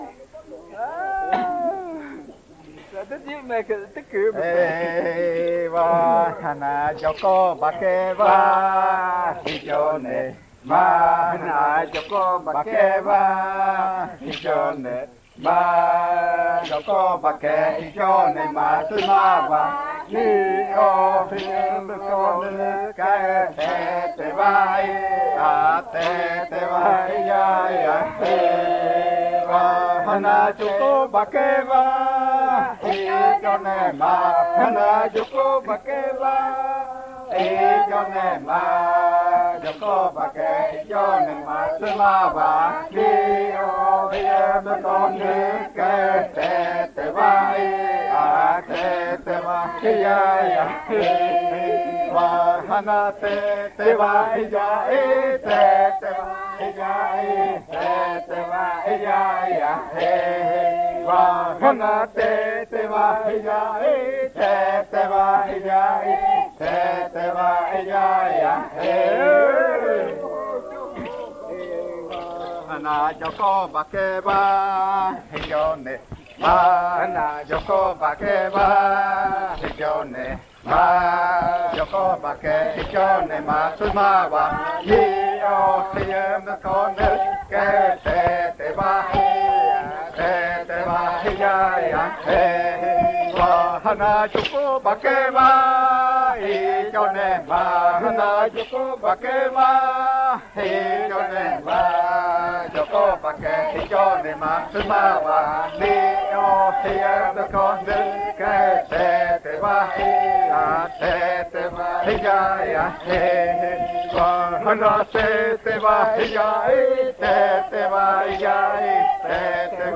31. Baile de nombramiento. Canto n°17
Puerto Remanso del Tigre, departamento de Amazonas, Colombia